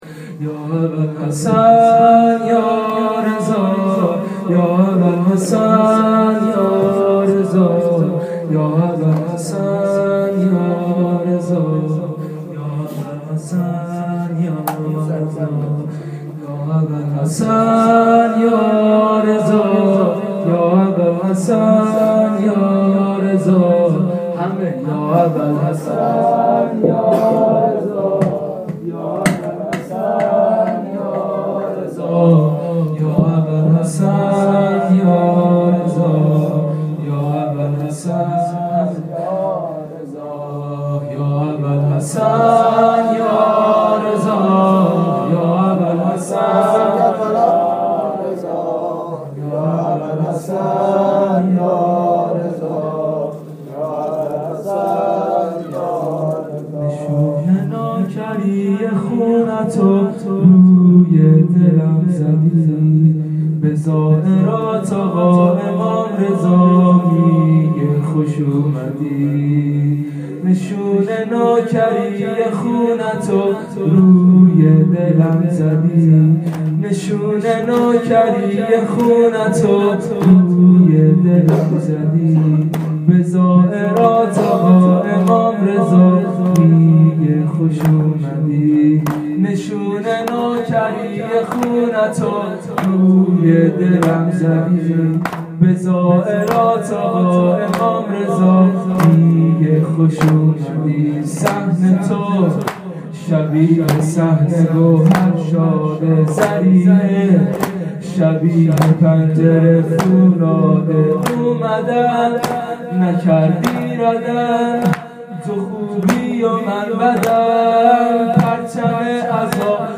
وفات حضرت معصومه (92)- زمینه